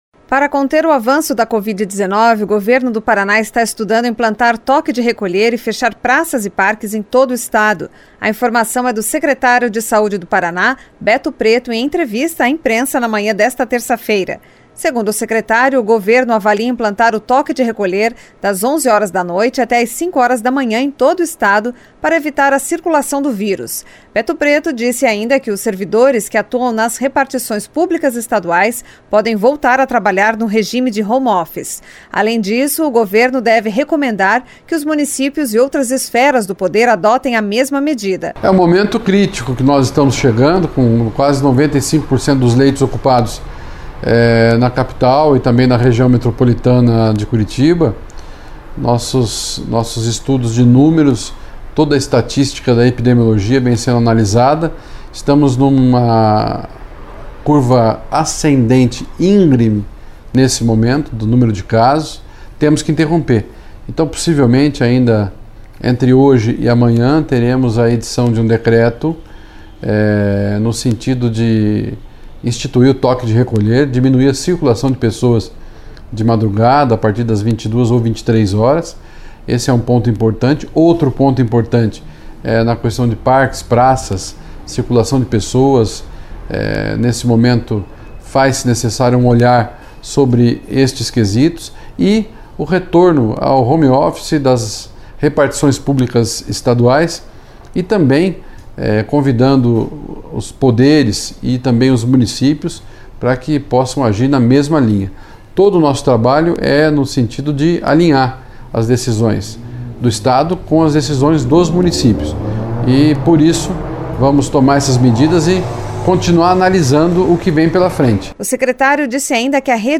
Para conter o avanço da Covid-19, o Governo do Paraná está estudando implantar toque de recolher e fechar praças e parques em todo o estado. A informação é do secretário de Saúde Beto Preto, em entrevista à imprensa na manhã desta terça-feira.